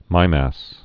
(mīmăs, mē-)